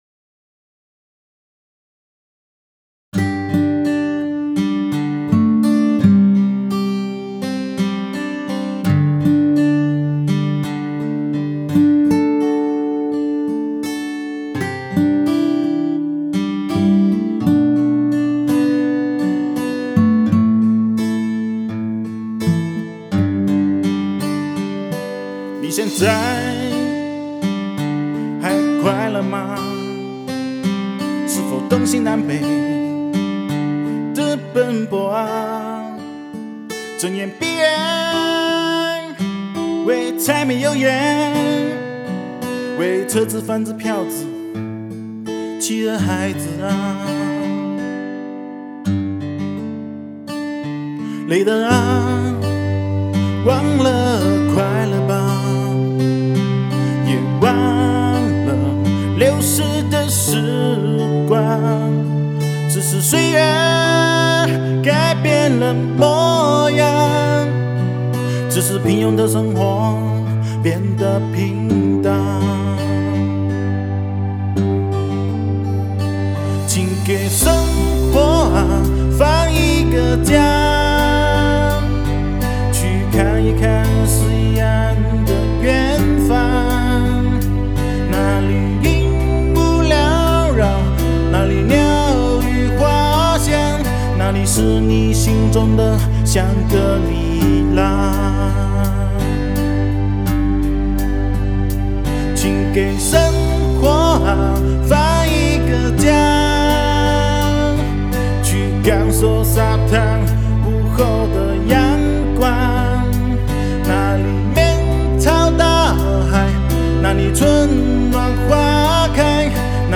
Ps：在线试听为压缩音质节选，体验无损音质请下载完整版 你现在还快乐吗？